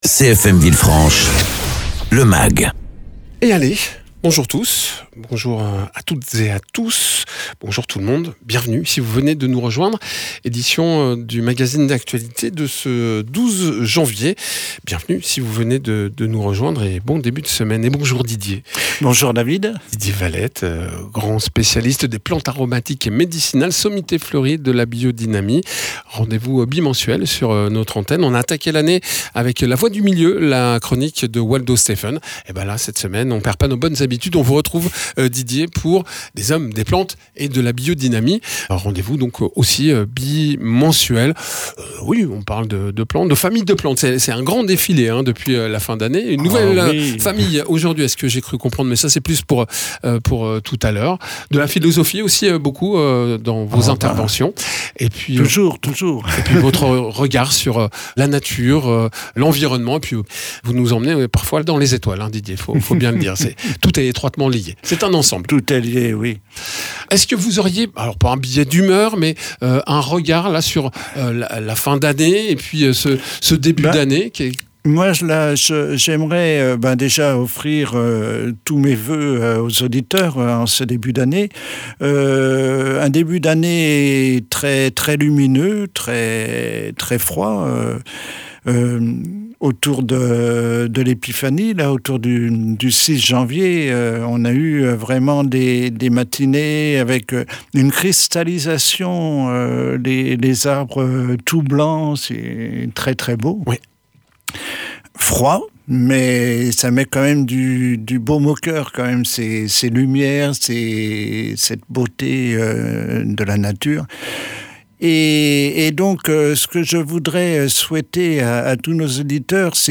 paysan spécialisé dans les plantes aromatiques et médicinales et la biodynamie.